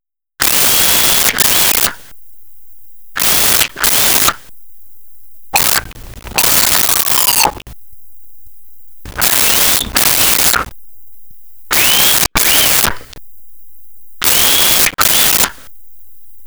Whistles Cat Calls
Whistles Cat Calls.wav